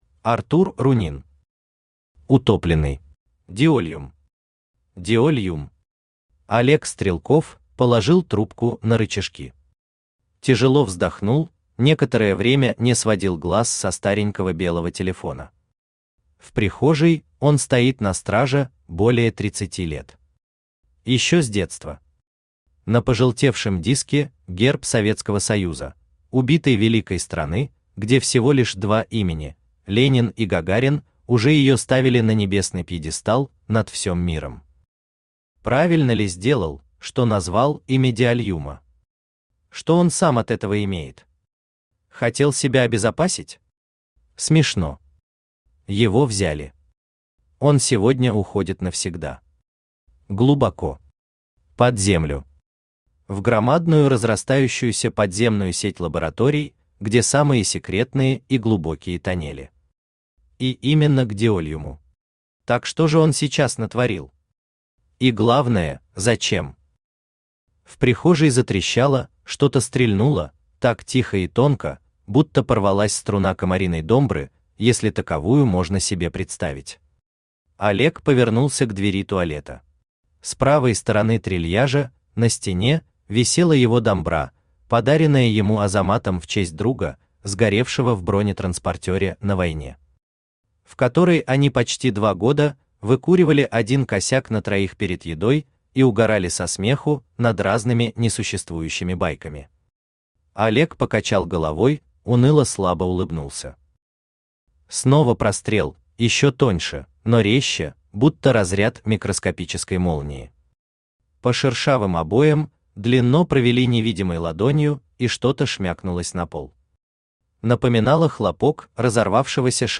Аудиокнига Утопленный | Библиотека аудиокниг
Aудиокнига Утопленный Автор Артур Рунин Читает аудиокнигу Авточтец ЛитРес.